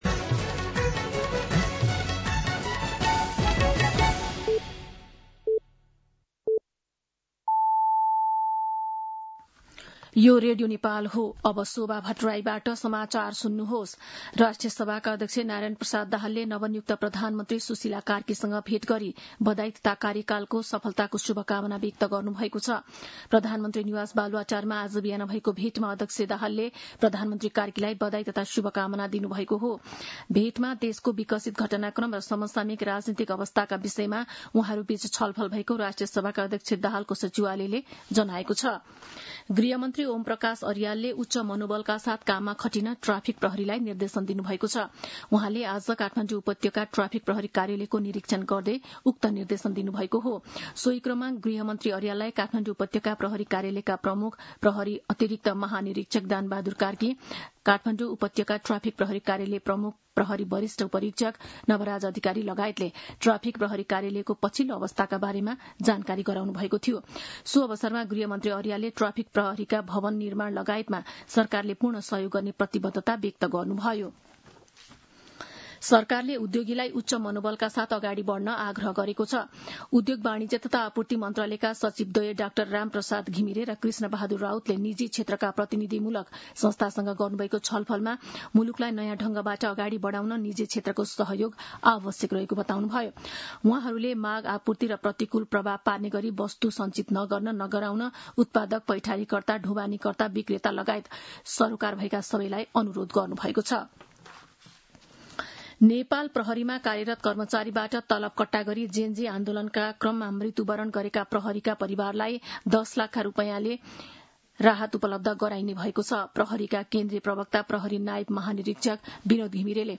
दिउँसो ४ बजेको नेपाली समाचार : ३१ भदौ , २०८२
4-pm-News-2.mp3